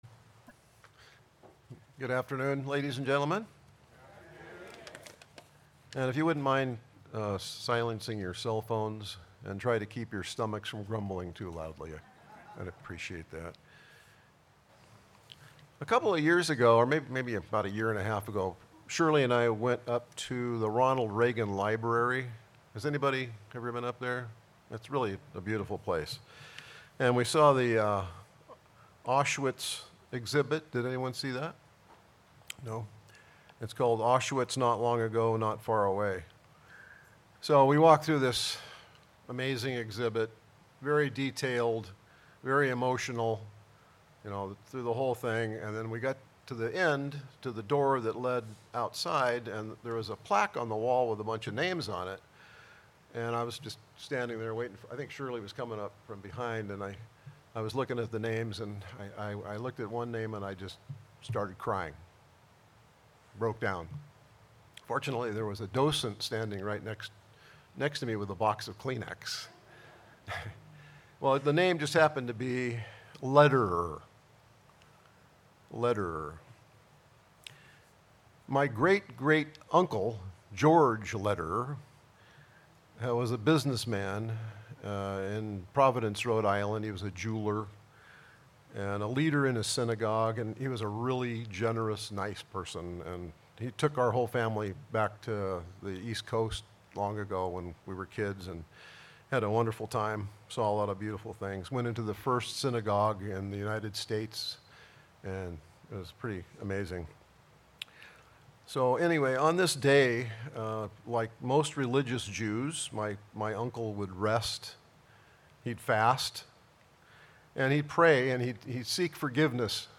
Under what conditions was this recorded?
Given in Orange County, CA